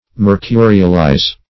Mercurialize \Mer*cu"ri*al*ize\, v. i.